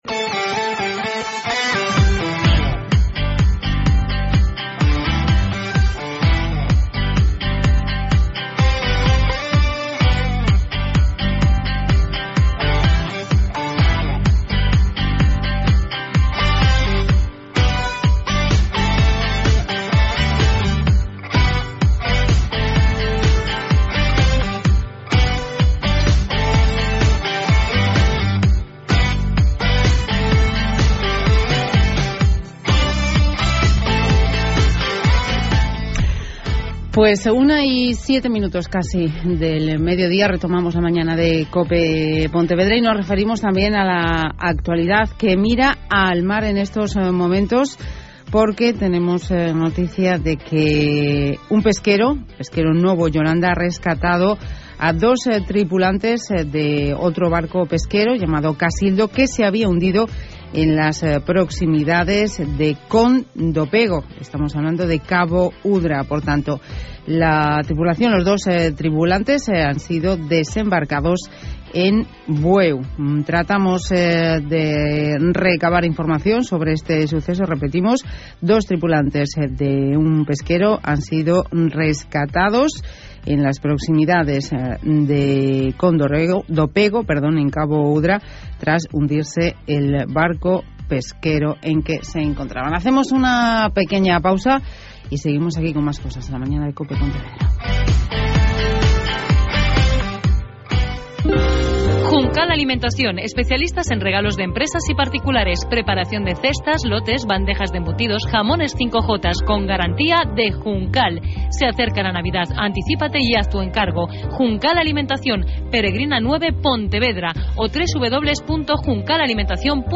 Mi intervención abarca desde el minuto 22:00